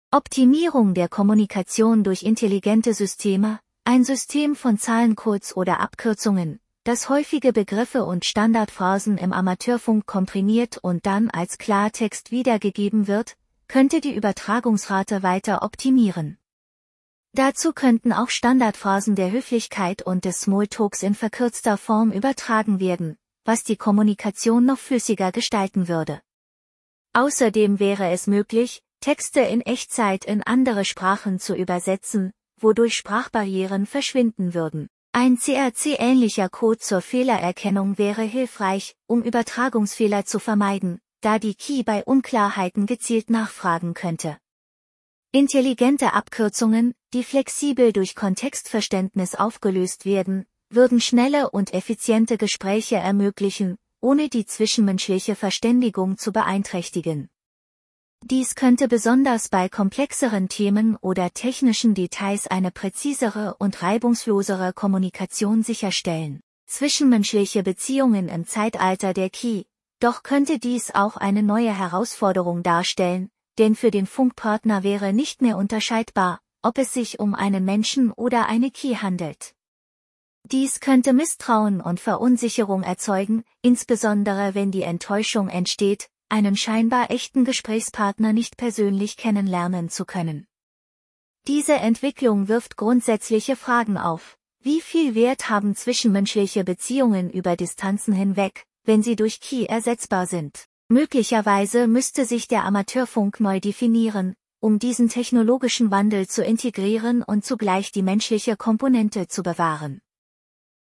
Diesen Text (Stand 2.10.24) in drei Abschnitten vorlesen lassen: Erstellt mit ebenfalls künstlichen Stimmen von TTSMAKER